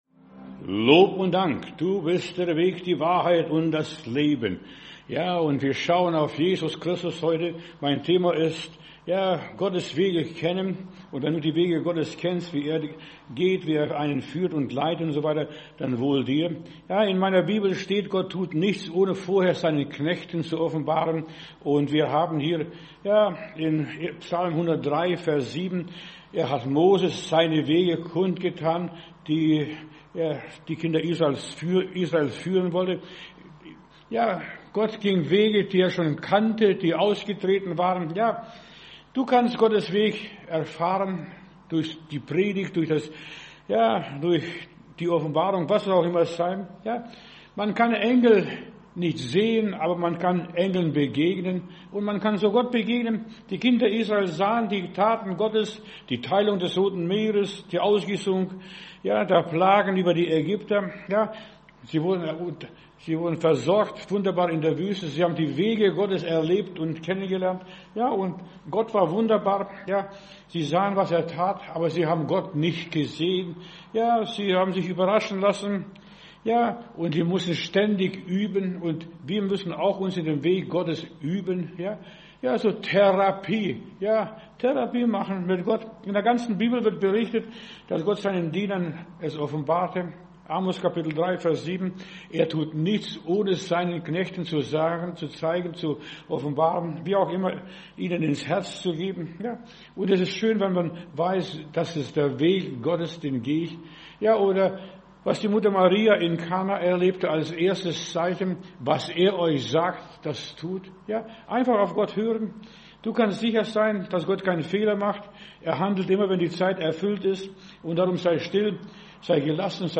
Predigt herunterladen: Audio 2026-01-16 Gottes Wege kennen Video Gottes Wege kennen